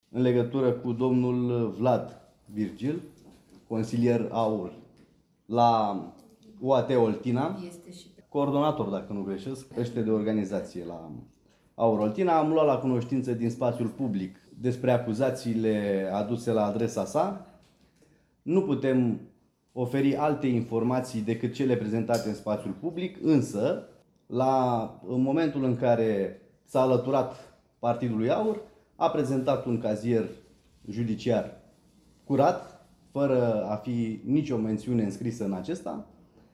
Într-o conferință de presă, deputatul Mohammad Murad a spus că România are nevoie acum de un proiect de țară în realizarea căruia să se implice toate partidele.